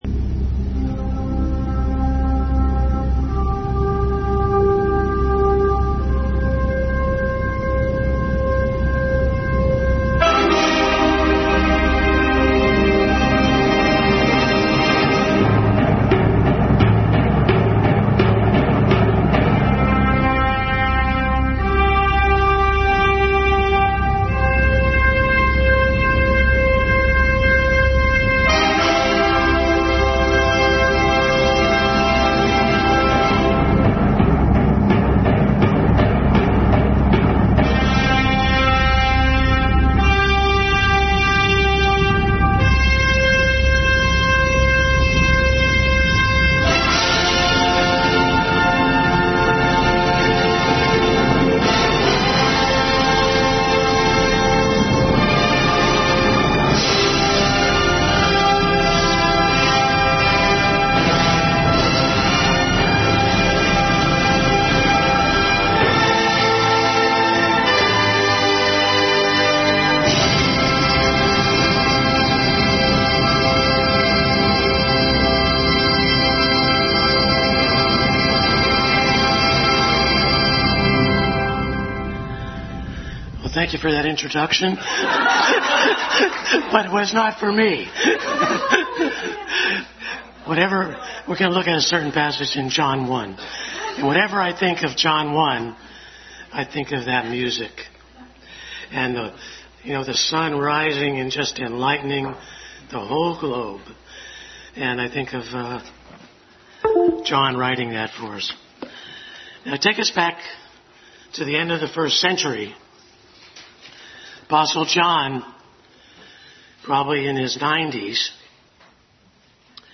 Bible Text: John 1:1-13 | Adult Sunday School Class.